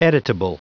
Prononciation du mot editable en anglais (fichier audio)
Prononciation du mot : editable